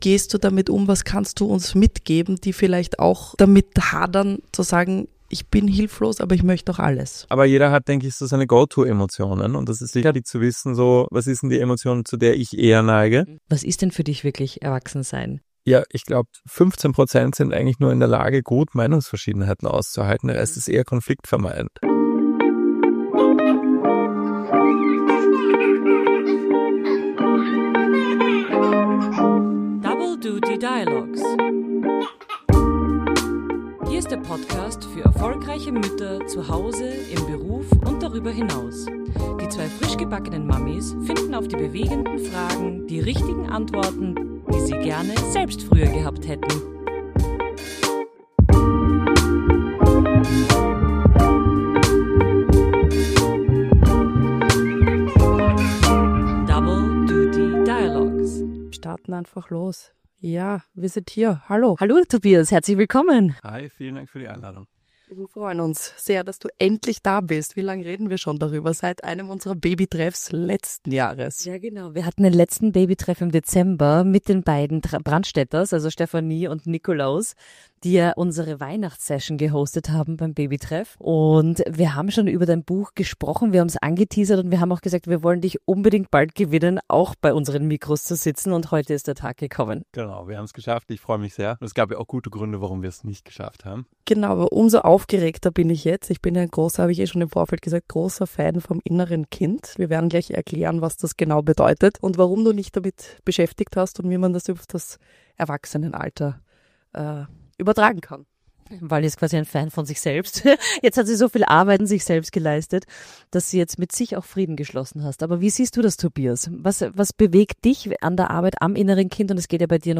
Ein ehrliches Gespräch über Erwartungen, Überforderung und die kleinen Momente, in denen man merkt, dass man vielleicht doch schon ein bisschen „erwachsen“ ist.